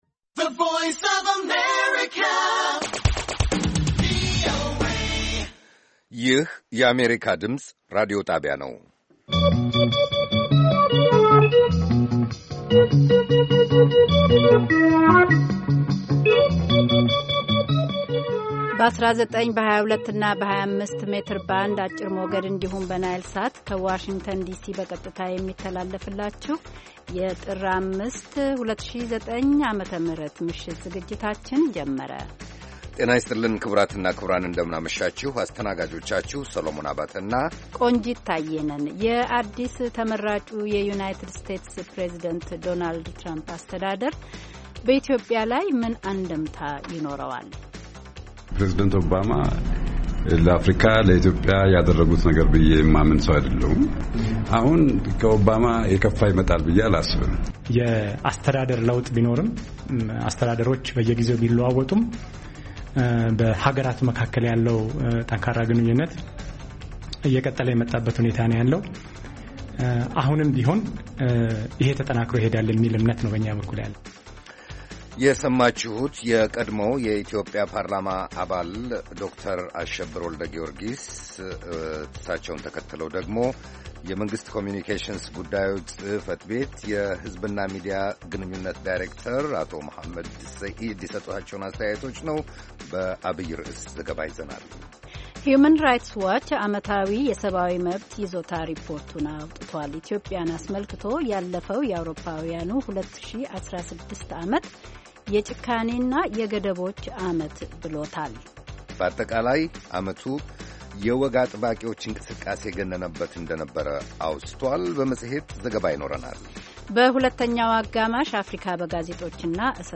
ቪኦኤ በየዕለቱ ከምሽቱ 3 ሰዓት በኢትዮጵያ አቆጣጠር ጀምሮ በአማርኛ፣ በአጭር ሞገድ 22፣ 25 እና 31 ሜትር ባንድ የ60 ደቂቃ ሥርጭቱ ዜና፣ አበይት ዜናዎች ትንታኔና ሌሎችም ወቅታዊ መረጃዎችን የያዙ ፕሮግራሞች ያስተላልፋል። ዐርብ፡- እሰጥ አገባ፣ አፍሪካ በጋዜጦች፡ አጥቢያ ኮከብ (የማኅበረሰብ ጀግኖች)